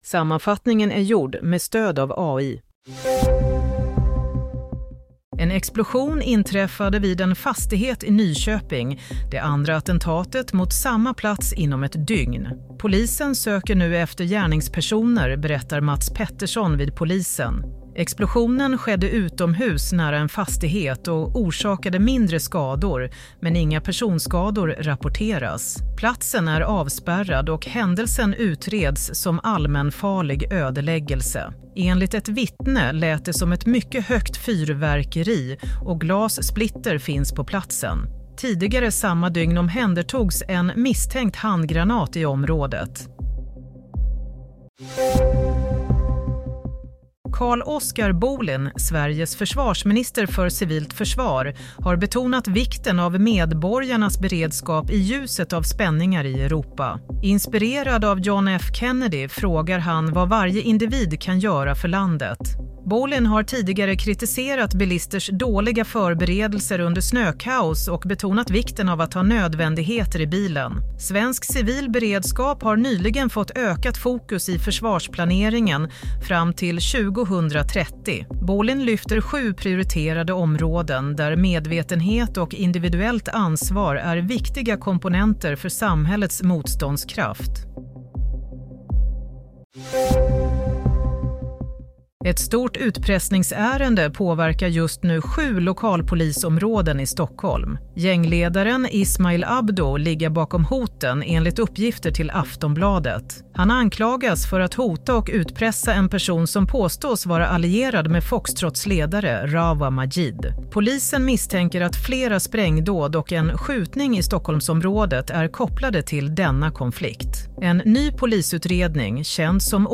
Nyhetssammanfattning - 14 januari 22:00
Sammanfattningen av följande nyheter är gjord med stöd av AI. - Explosion i centrum – stor insats i Nyköping - Uppgifter: Person hotas att betala miljonsummor – adresser i hela Stockholm bevakas - Bohlin: Du förväntas ta ansvar under väldigt störda omständigheter